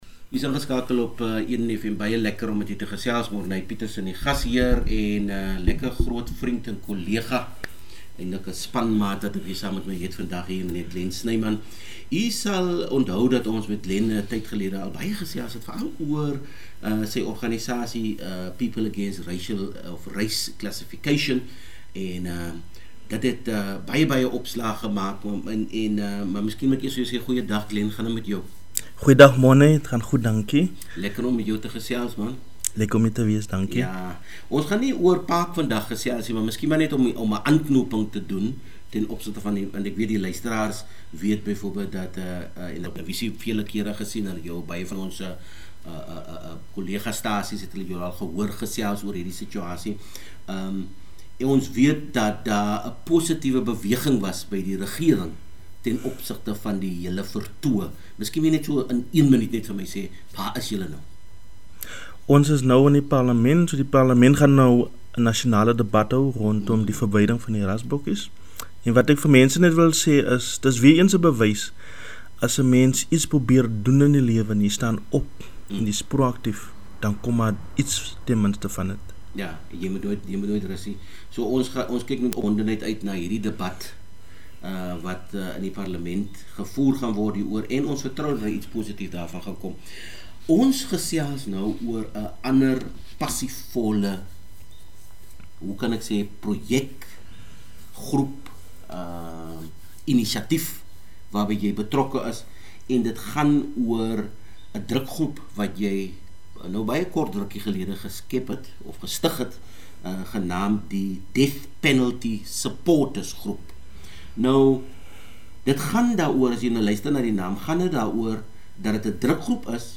Edenfm interview